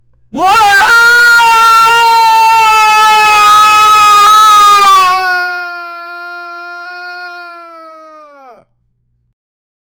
Voice trembling, weak, and fading as if injured on a chaotic battlefield with distant clashes and muffled shouts in the background." 0:10 Baccha Rone ki awaaz around 1 year old 0:10
single-male-voice-screami-b67k75o2.wav